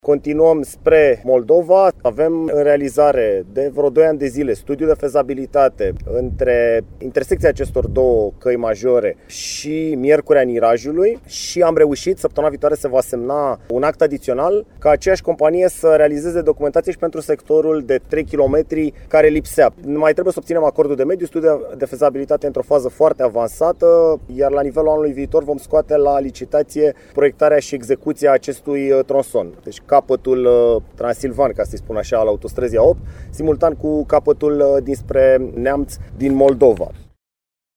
Ministrul Cătălin Drulă a mai explicat că sunt în pregătire tot din această zonă legături între Autostrada Transilvania și A8: